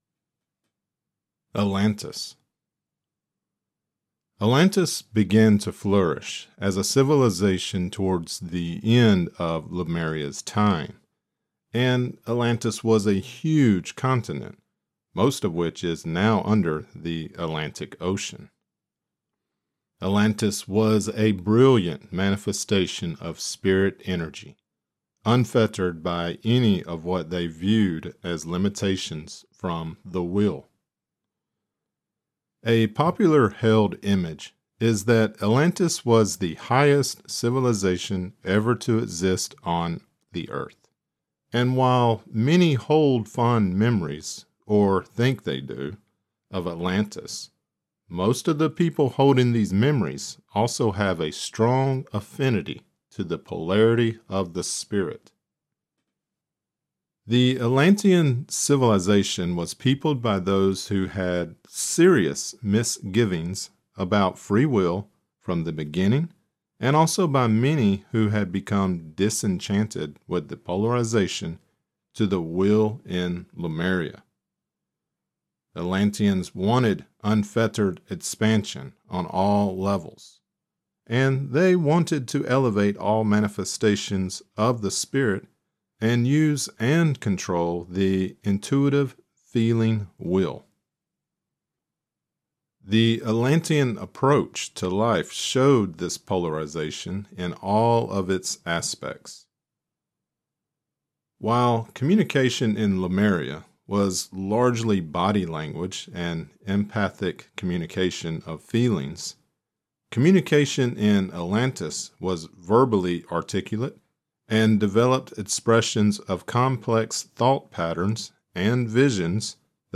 Lecture Created Transcript Blockchain Atlantis 01/28/2026 Atlantis (audio only) 01/28/2026 Watch Right Use of Will part 21 lecture: Right Use of Will lectures are also located on the Cosmic Repository video site .